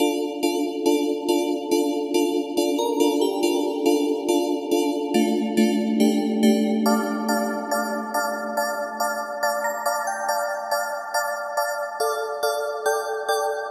铃铛
描述：分离的钟声 (上勾拳)
Tag: 140 bpm Trap Loops Bells Loops 2.31 MB wav Key : A